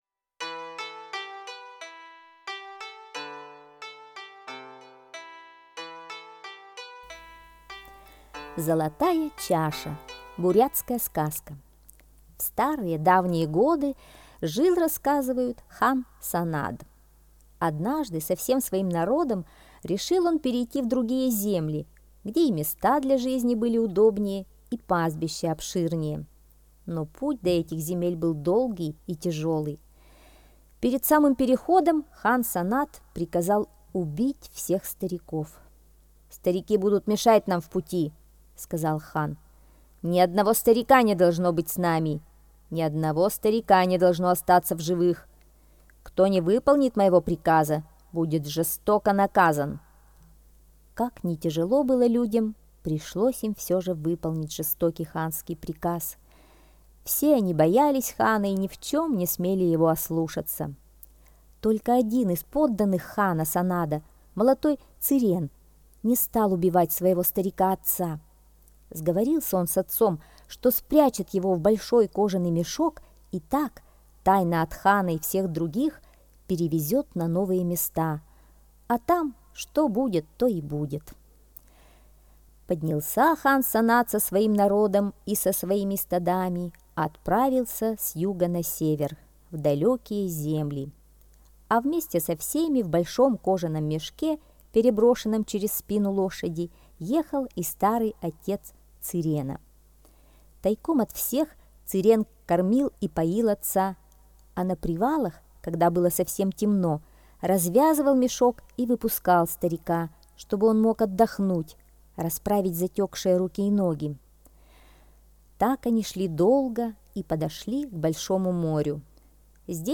Золотая чаша - бурятская аудиосказка - слушать онлайн